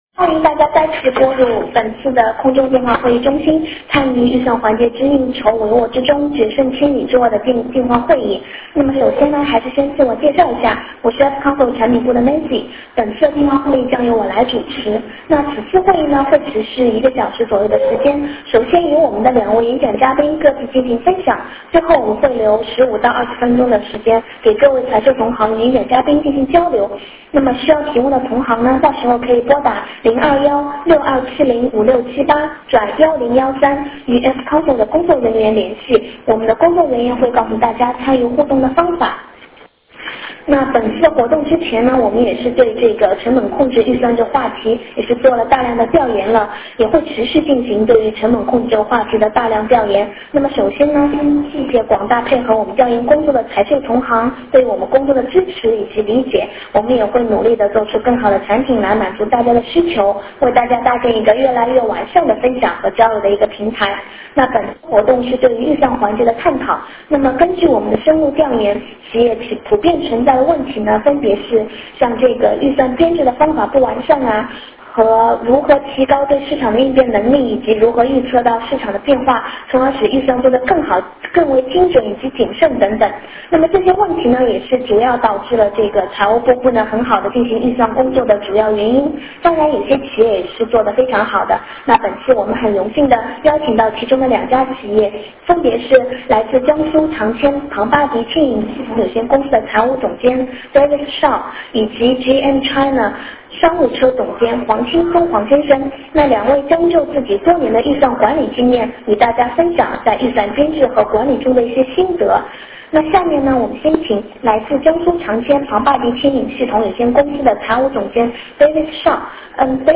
两位分享嘉宾各自分享
Q&A互动环节 参会者针对实际操作中遇到的问题进行提问交流